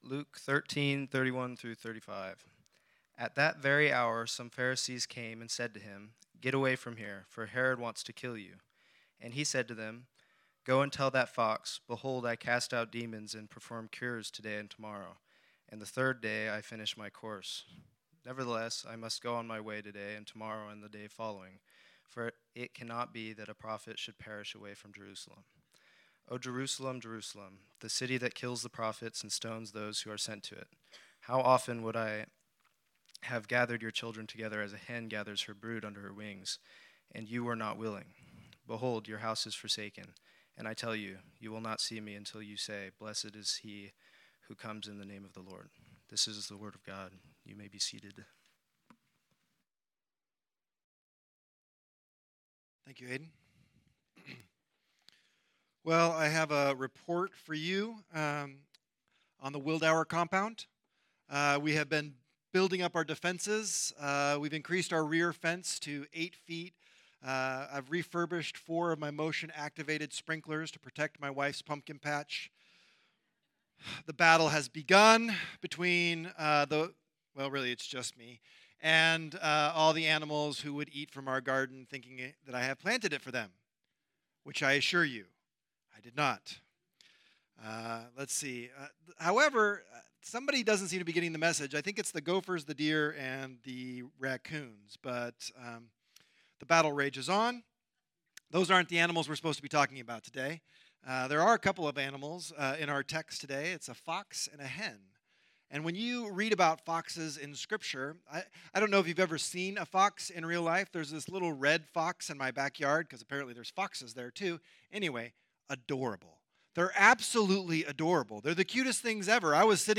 Type: Sermon